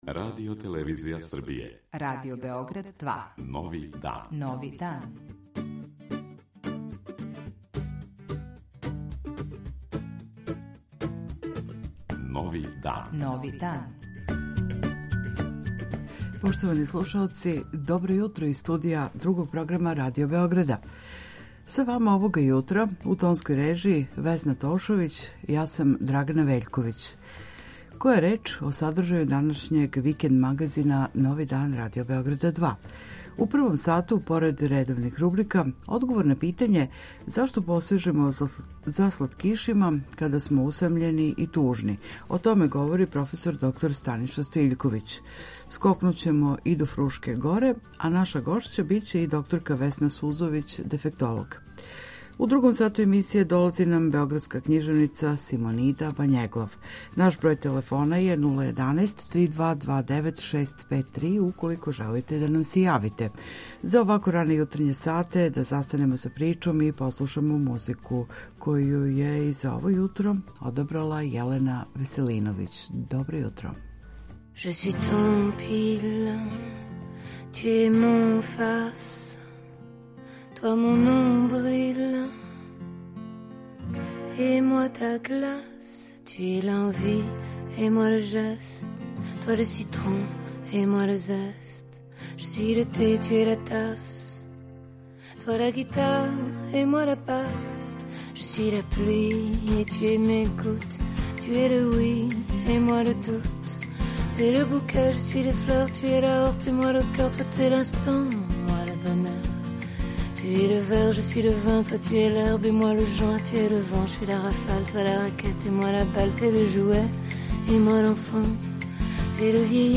Јутарње заједничарење на таласима РБ 2